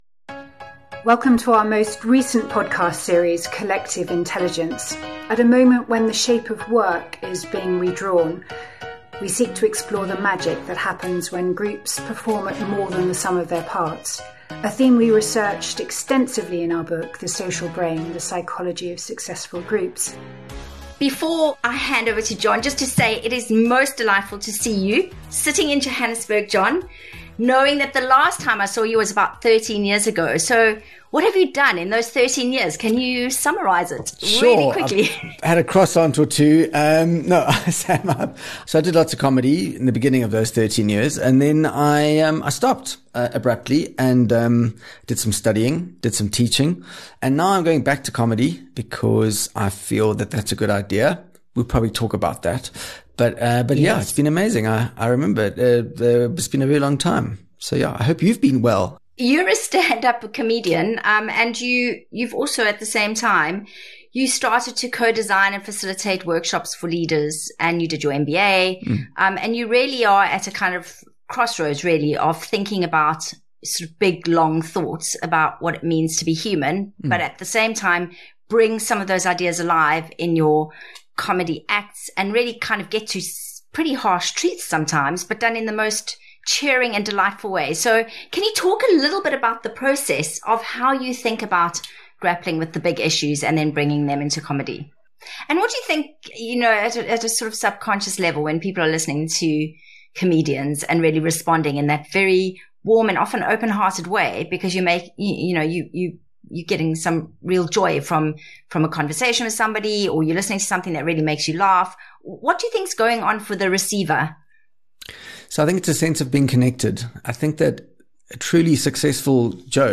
comedian, leader, and educator John Vlismas